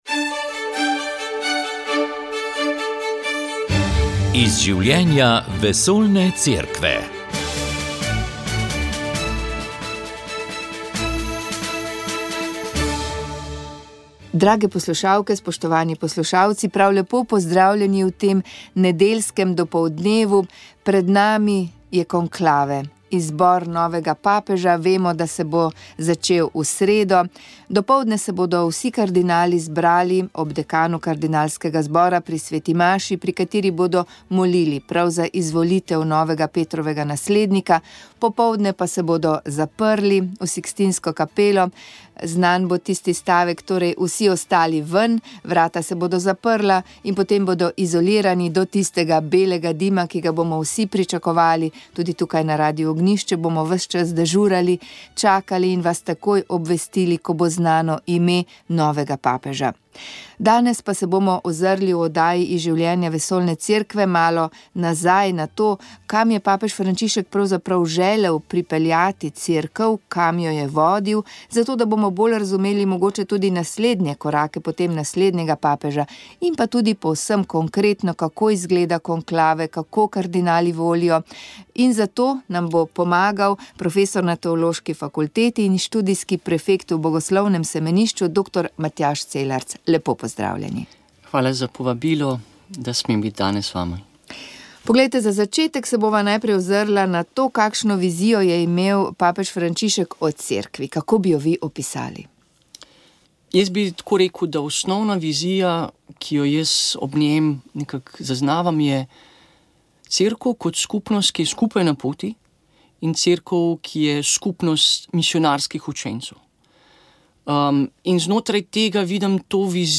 Molitev je vodil ljubljanski nadškof msgr. Stanislav Zore.